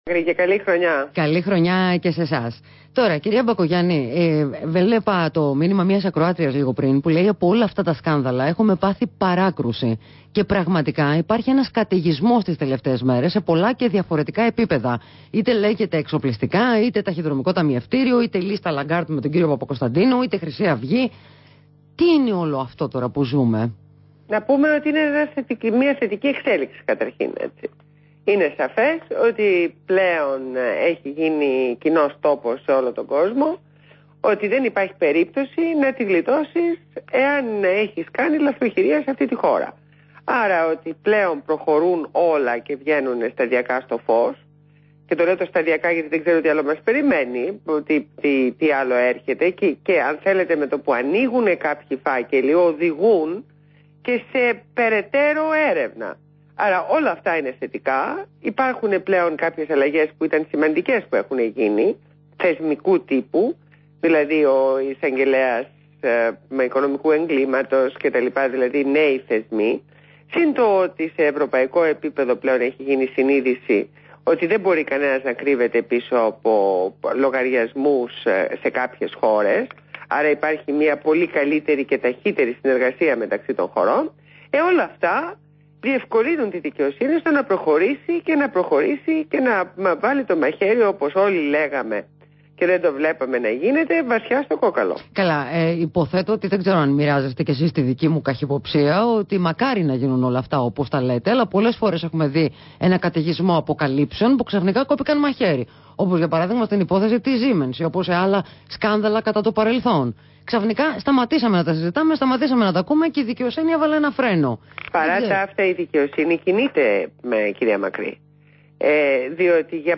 Ραδιοφωνική συνέντευξη στον REALfm 97,8